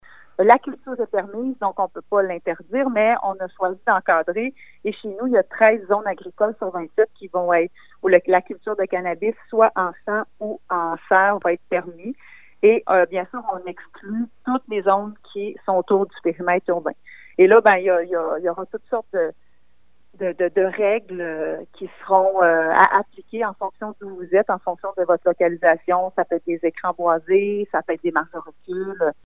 La mairesse Geneviève Dubois précise, en audio c-joint,  l’orientation prise par le conseil municipal :